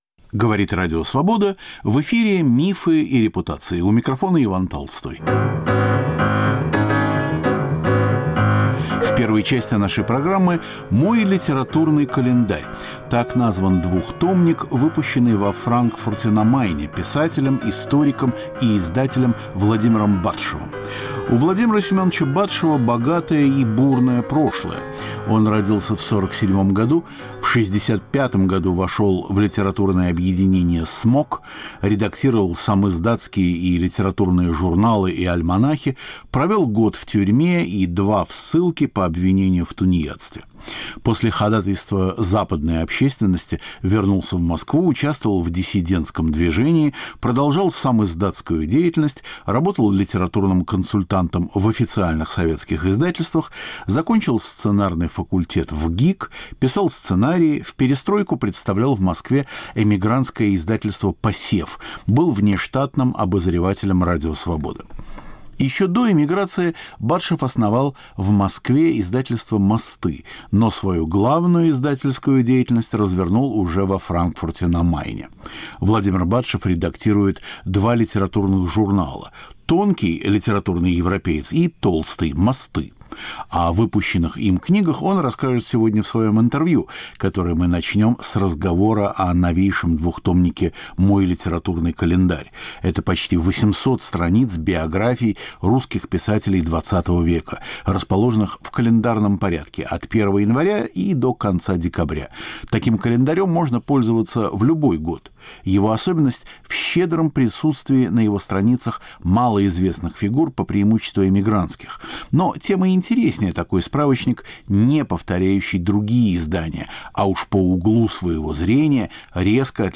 Мифы и репутации. В первой части - беседа с писателем